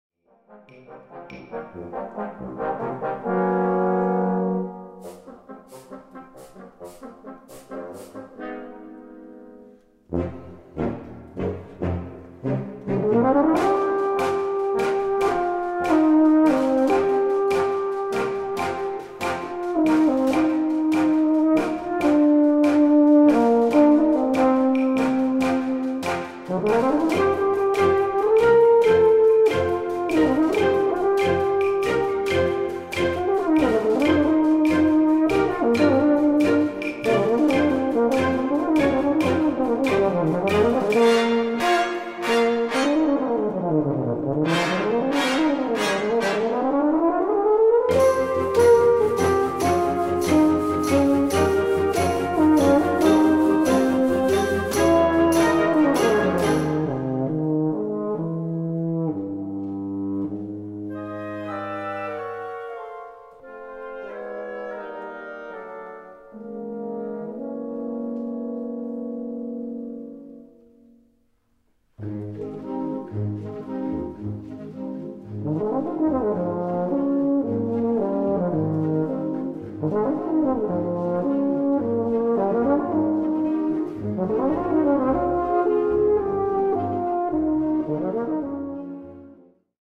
Solo für Euphonium und Blasorchester
Besetzung: Blasorchester